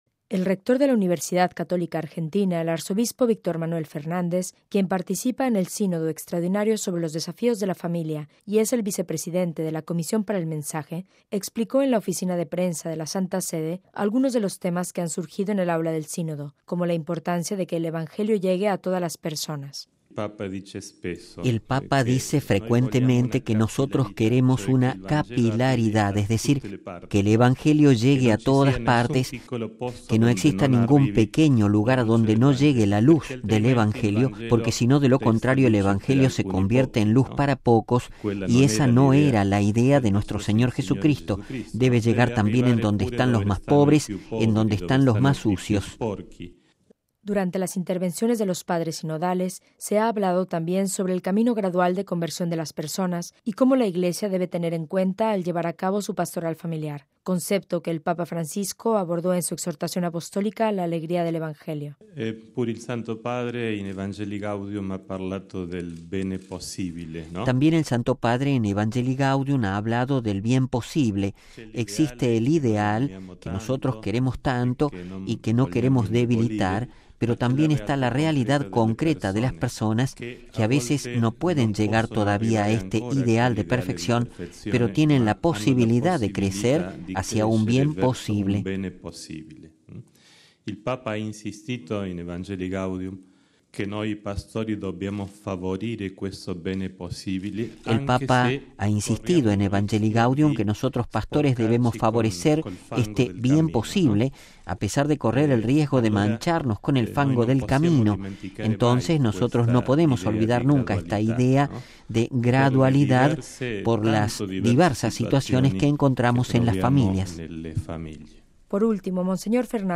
MP3 El rector de la Universidad Católica Argentina, el arzobispo Víctor Manuel Fernández -quien participa en el Sínodo extraordinario sobre los desafíos de la familia y es el vicepresidente de la comisión para el mensaje- explicó en la Oficina de Prensa de la Santa Sede algunos de los temas que han surgido en el Aula del Sínodo, como la importancia de que el Evangelio llegue a todas las personas.